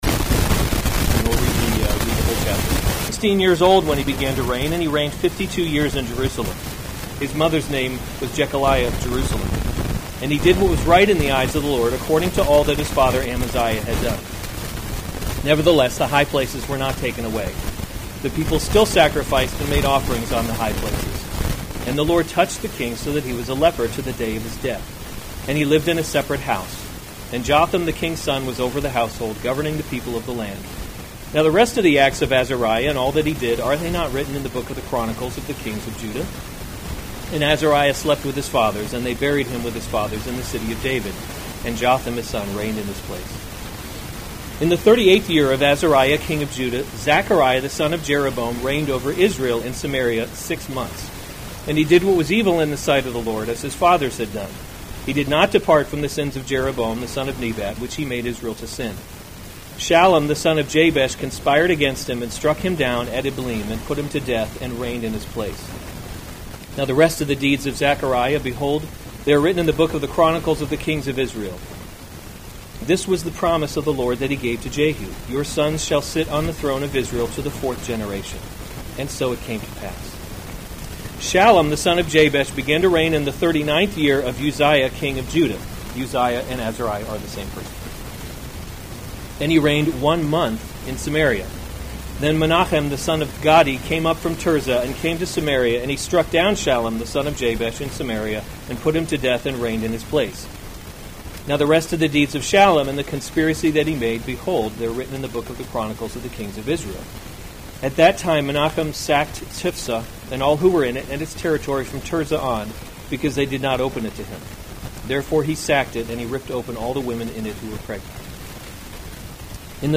August 8, 2021 2 Kings – A Kingdom That Cannot Be Shaken series Weekly Sunday Service Save/Download this sermon 2 Kings 15:1-38 Other sermons from 2 Kings Azariah Reigns in […]